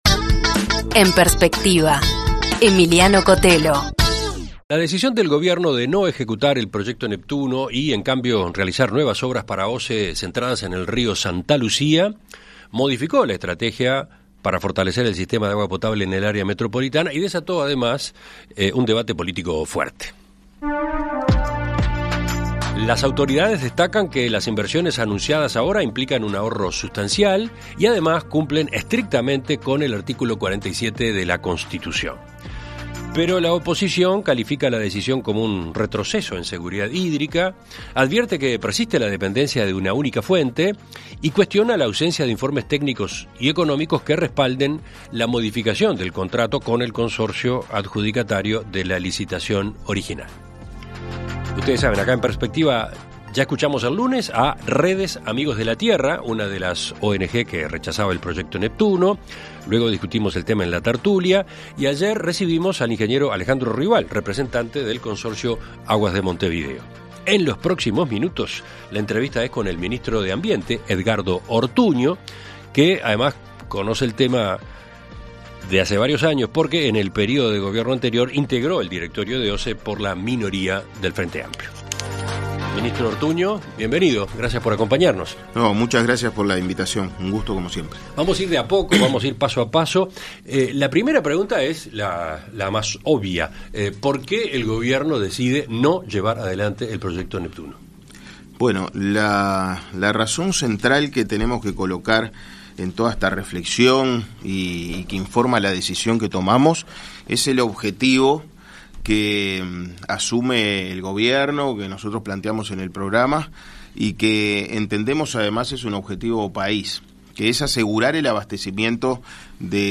En Perspectiva Zona 1 – Entrevista Central: Edgardo Ortuño - Océano